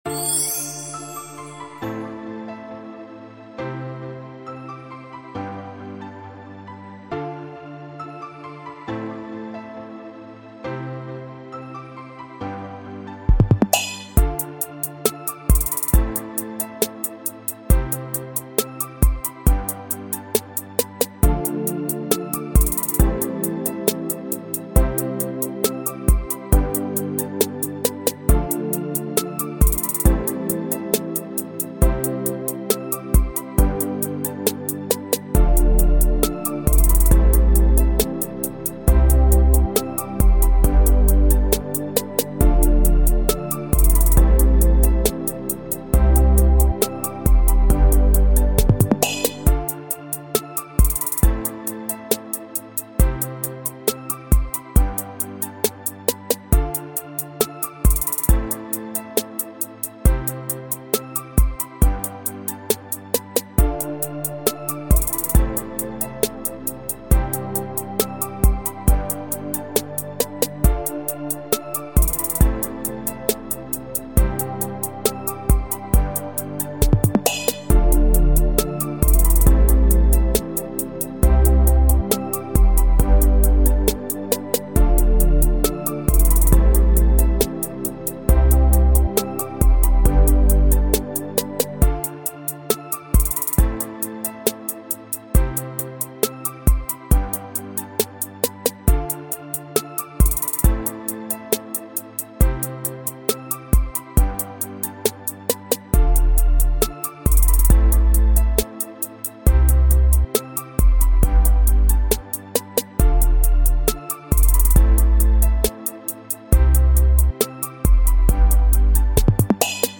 Hip hop free beat instrumental